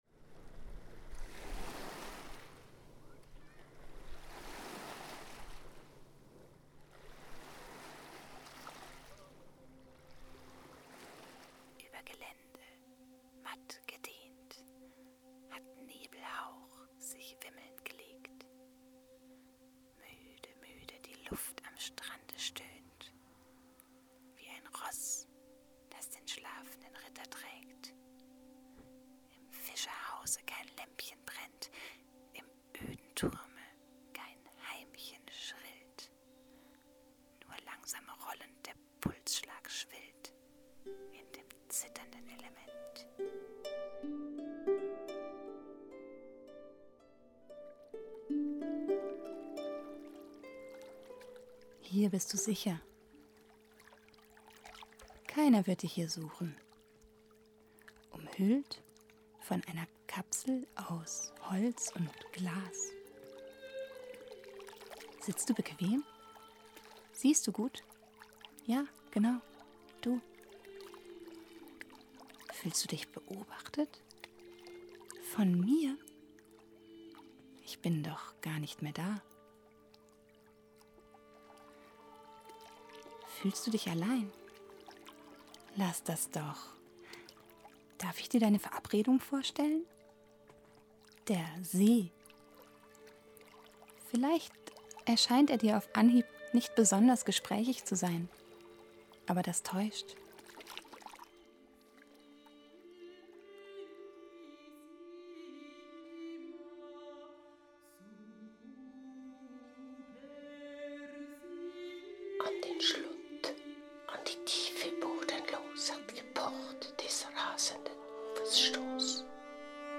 Klänge, Poesie und Legenden rund um den Bodensee
Viele Nuancen von Geräuschen sind dabei herausgekommen – oberhalb wie unterhalb der Wasseroberfläche.
Es sind ebenso vorbei ratternde Züge zu hören oder aneinander schlagende Segelstangen.
Dabei kommen unter anderem die klappernden Segelstangen zum Tragen, genauso wie Kratzgeräusche der Güterloren im Stolleninneren oder bedrohliche Karbatschenklänge.
Als Nebelmännle-Stimme erklingt eine Männerstimme des Vokalconsort Mainz.
In Gesang-und Sprechgesang-Einlagen